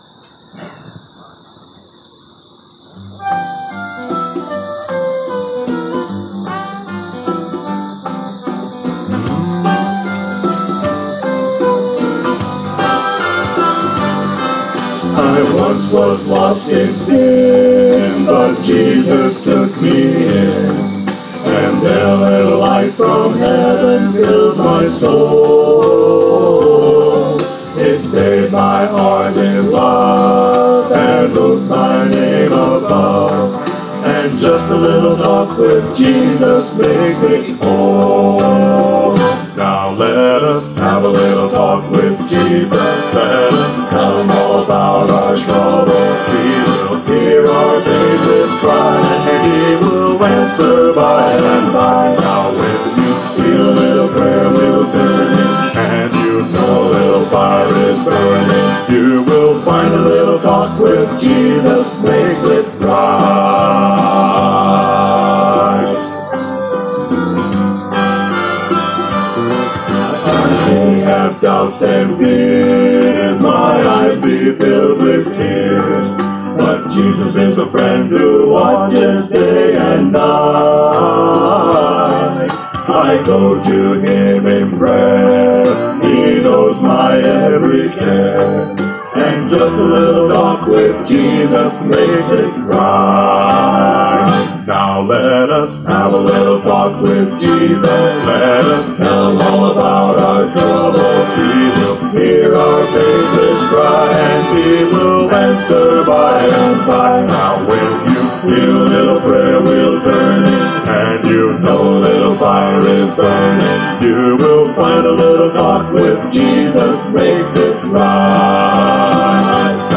Evangelism Event in the Park – Praise!